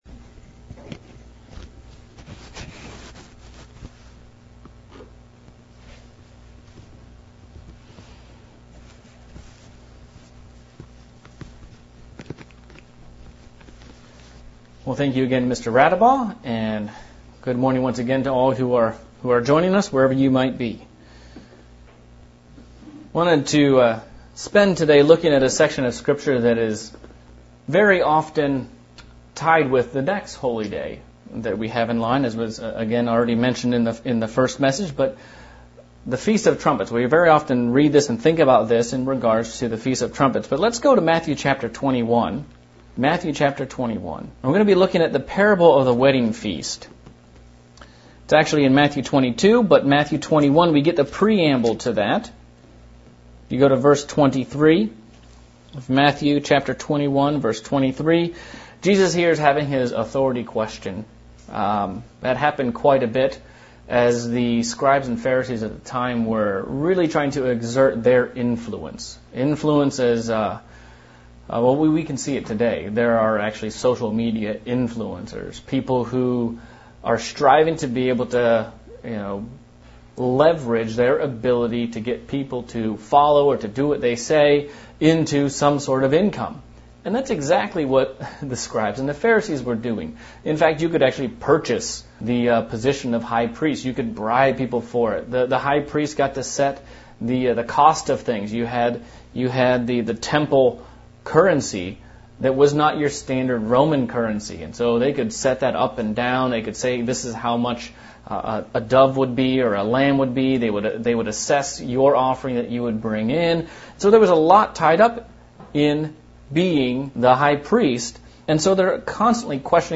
Pentecost Sermon on making the inside of the cup clean and bright so that it is pleasing to God